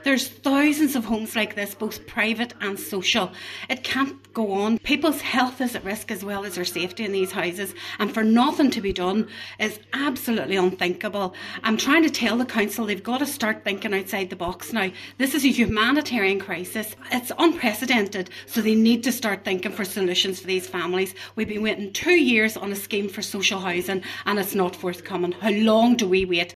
Cllr Beard says the council must now inspect each and every one of its social housing units for signs of mould and damp, and where necessary, take immediate remedial action: